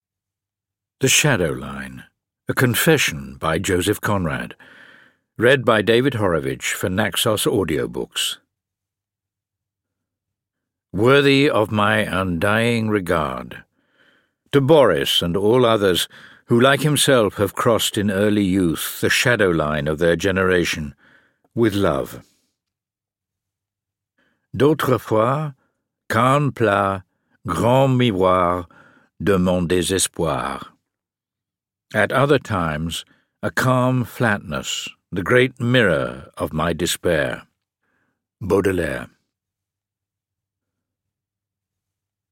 The Shadow-Line (EN) audiokniha
Ukázka z knihy
• InterpretDavid Horovitch